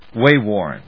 アクセント・音節wáy・wòrn 発音を聞く